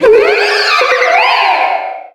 Cri de Méga-Lockpin dans Pokémon Rubis Oméga et Saphir Alpha.
Cri_0428_Méga_ROSA.ogg